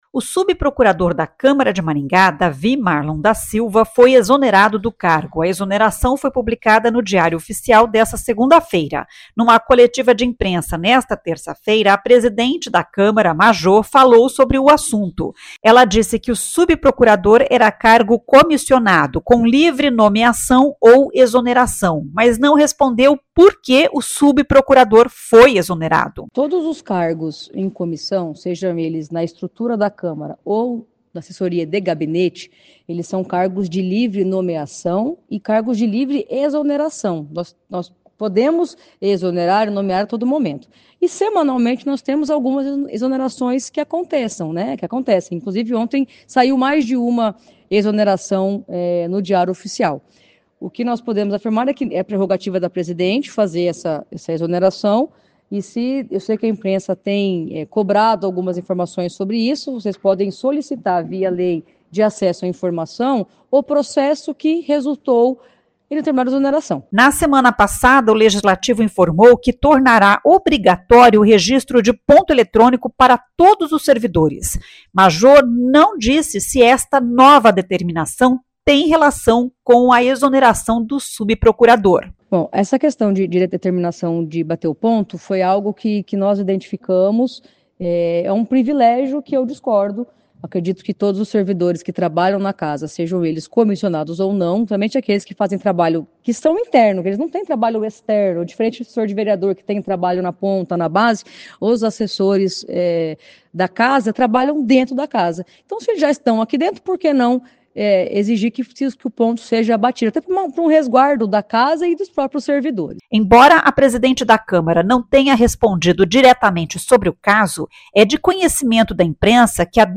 Numa coletiva de imprensa nesta terça-feira (22), a presidente da Câmara, Majô, falou sobre o assunto.